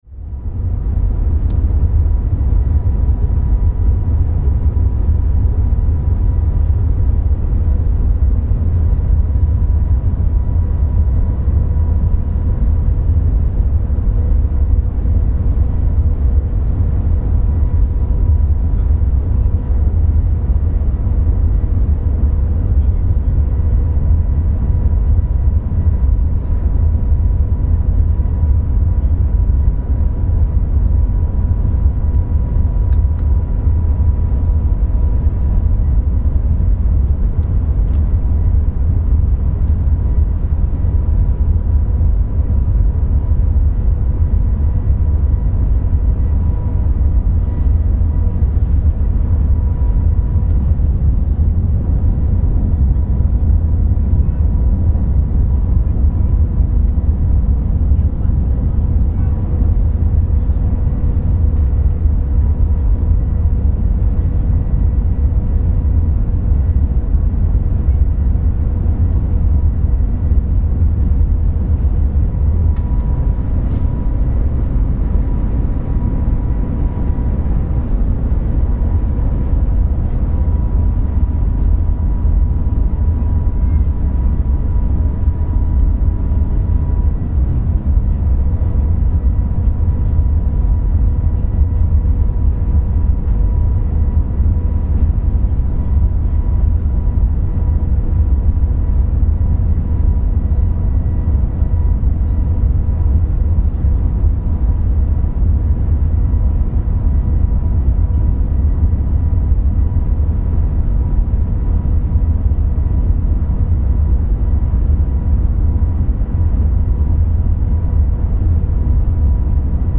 Travelling at 300km/h on the MagLev train